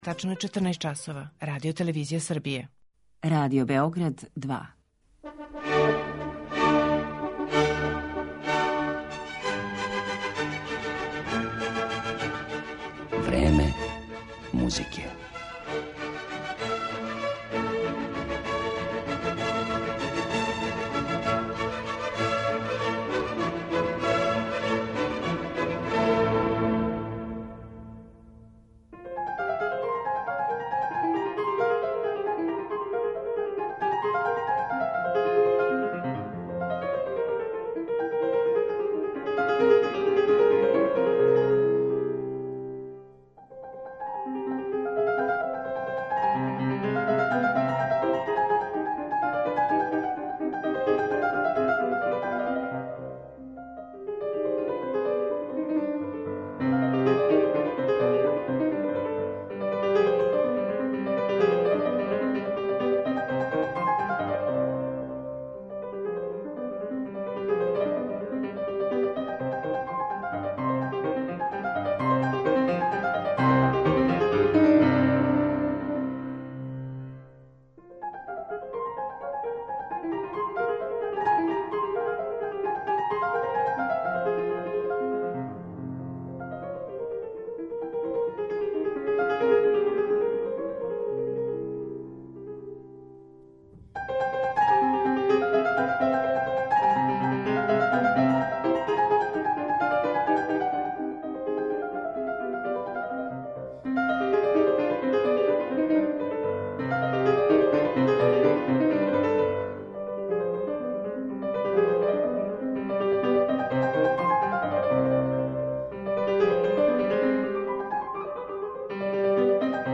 Сутра увече у Коларчевој задужбини, у оквиру концертне сезоне 'Steinwey & sons', наступа Јевгениј Судбин, уметник за кога многи музички критичари прогнозирају да ће бити највећи пијаниста 21. века.
У данашњем Времену музике уметност Јевгенија Судбина представићемо његовим интерпретацијама дела Доменика Скарлатија, Јозефа Хајдна, Волфганга Амадеуса Моцарта, Лудвига ван Бетовена, Сергеја Рахмањинова и Александра Скрјабина.